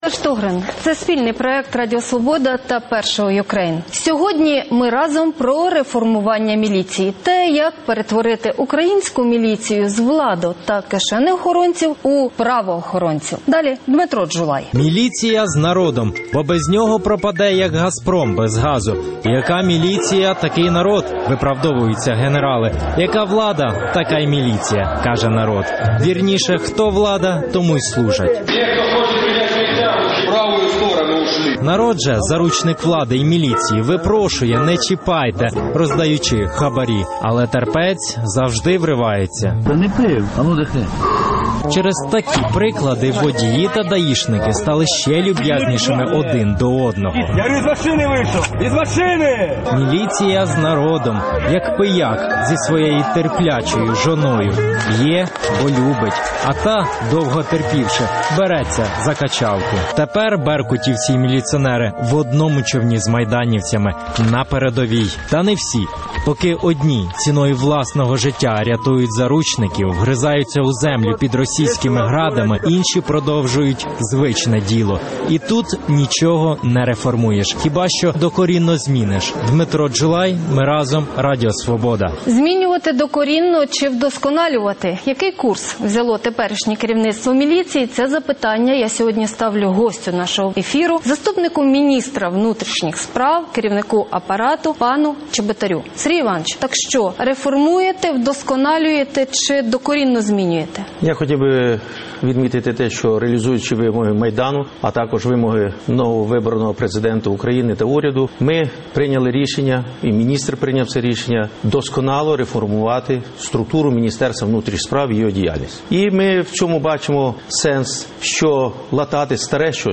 Про це говоримо із заступником Міністра внутрішніх справ, головою апарату відомства Сергієм Чеботарем